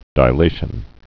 (dī-lāshən, dĭ-)